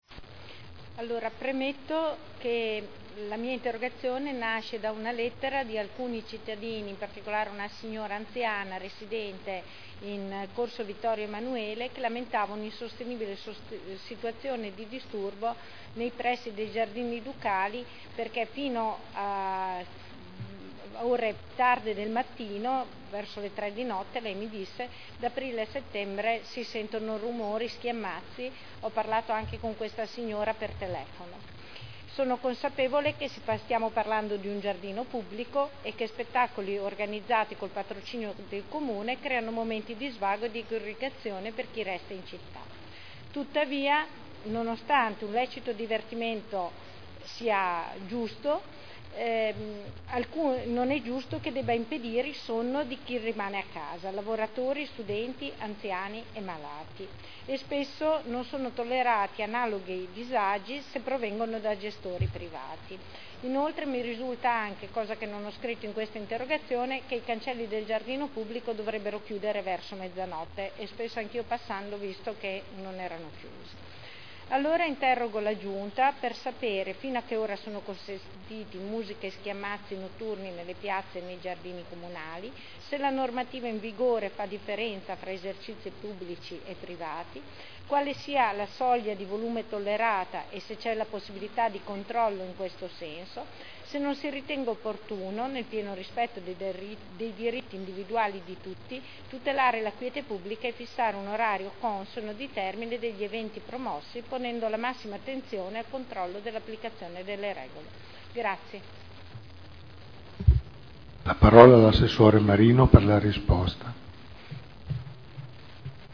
Luigia Santoro — Sito Audio Consiglio Comunale